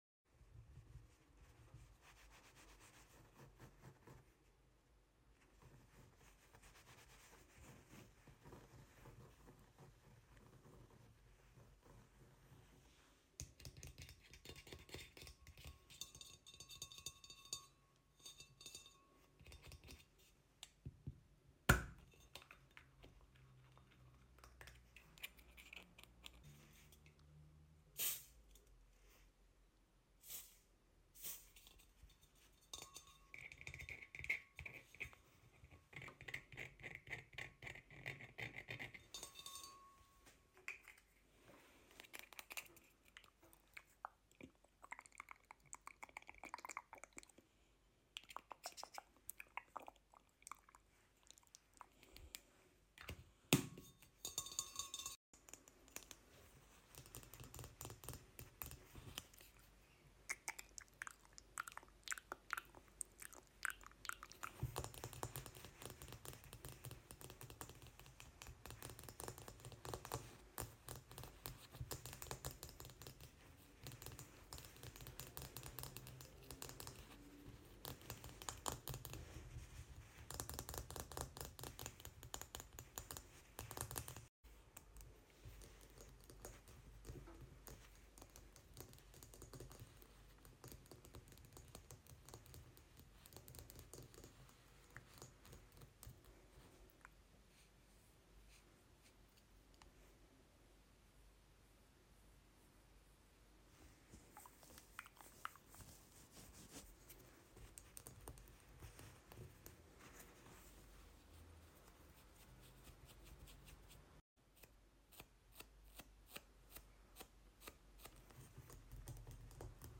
Bathroom asmr!